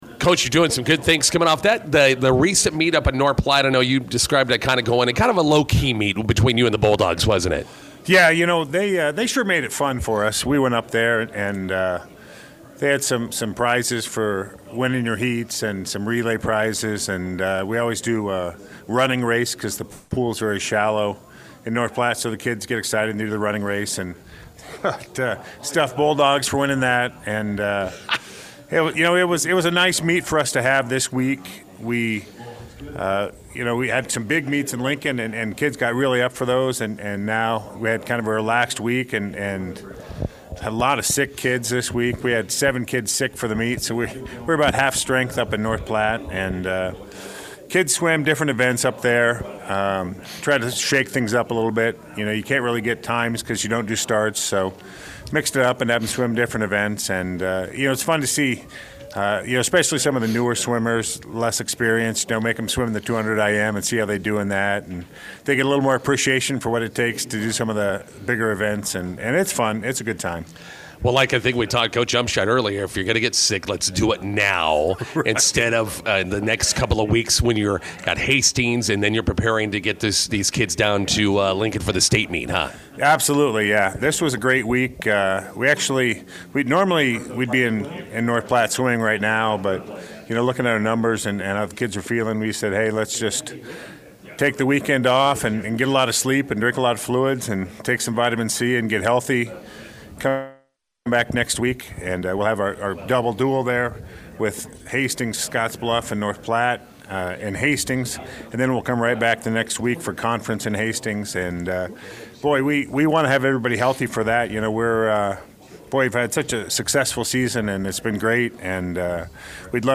INTERVIEW: Bison swimmers/divers look to add more state qualifiers this weekend in Hastings.